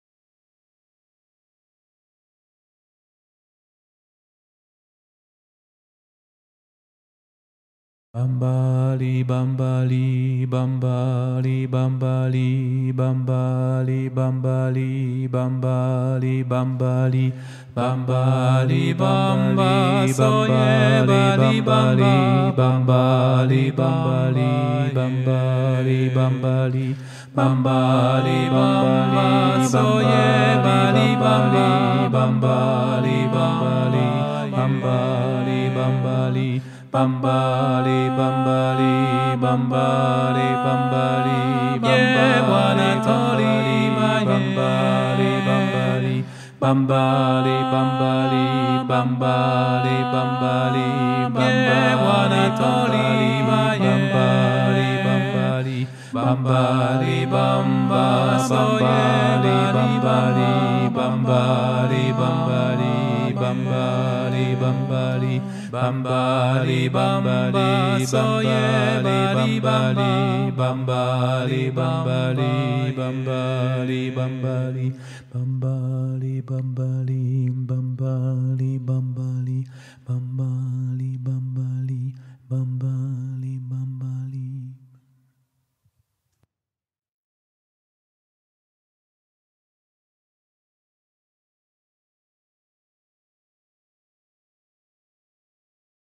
- Chant traditionnel du Sénégal
MP3 versions chantées